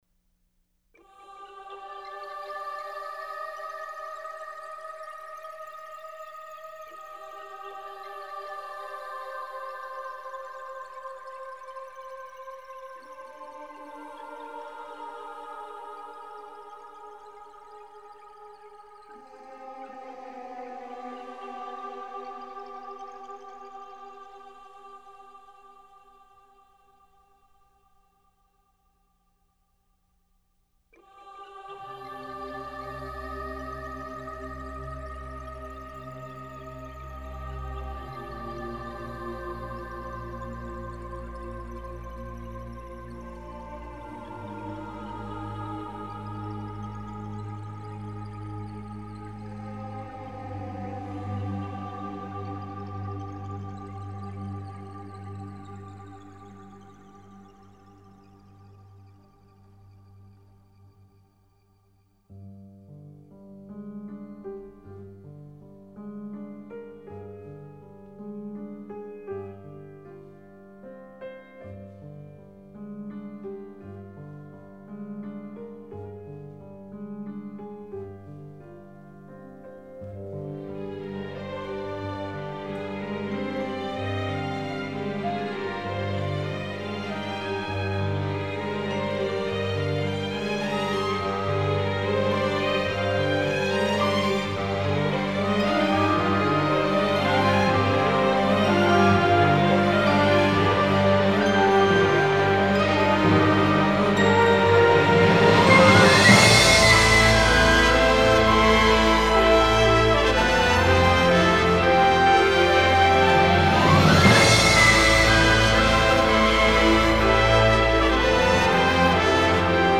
chamber orchestra, rhythm section and synth